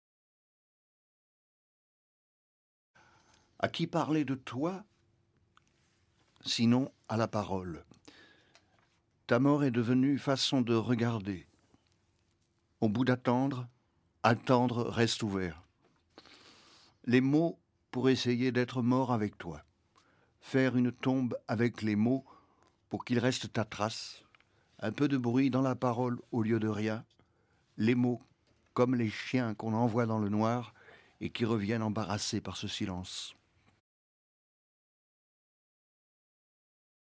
Jacques Bonnaffé lit "doucement avec l'ange" de Ludovic Janvier (0'37)